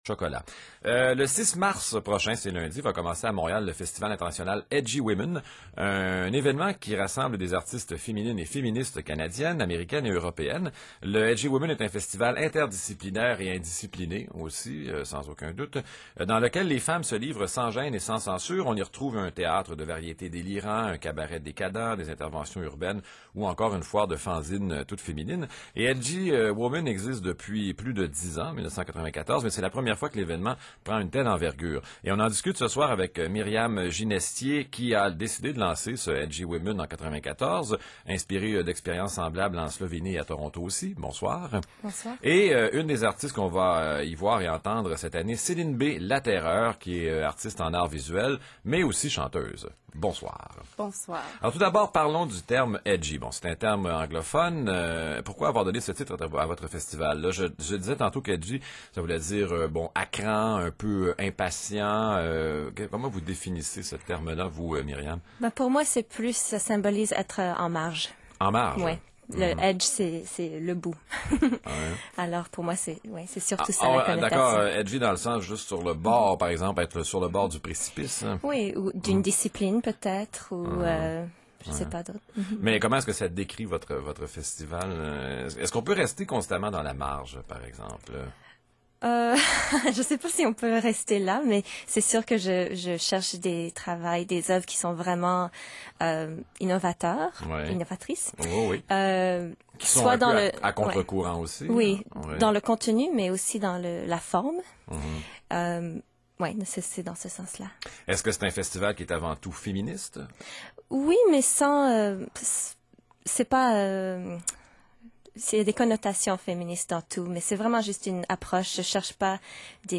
Entrevue.mp3